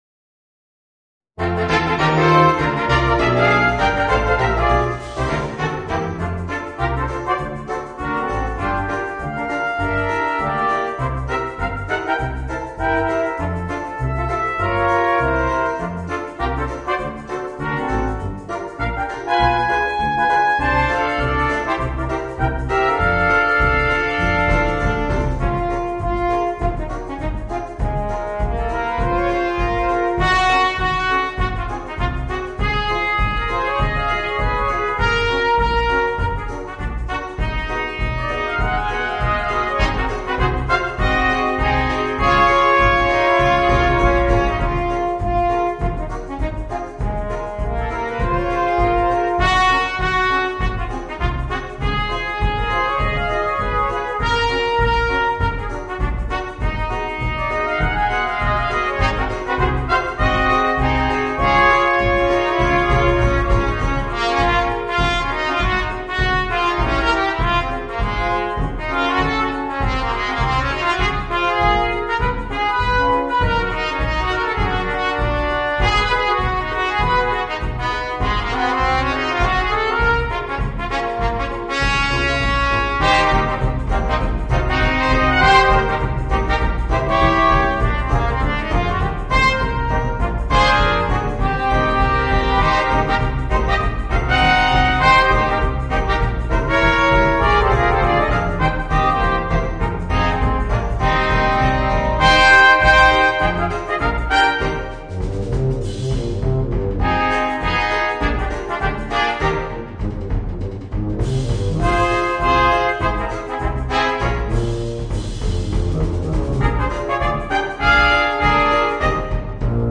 Voicing: 2 Trumpets, Trombone and Tuba